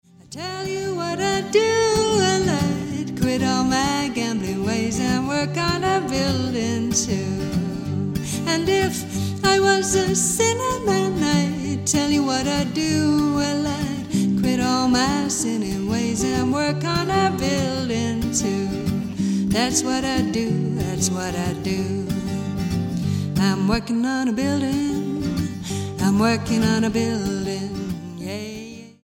STYLE: Roots/Acoustic
1960s blues folk vein